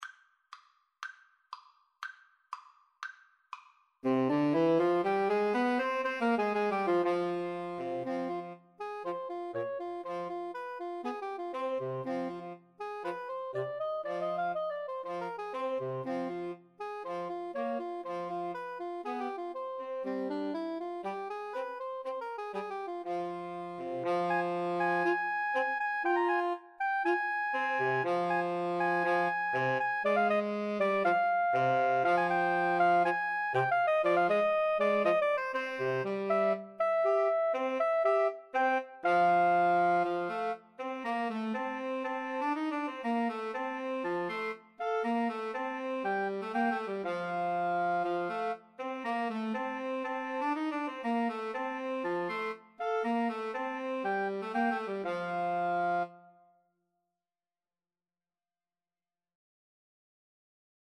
Soprano SaxophoneAlto SaxophoneTenor Saxophone
C minor (Sounding Pitch) (View more C minor Music for Woodwind Trio )
Allegro (View more music marked Allegro)
2/4 (View more 2/4 Music)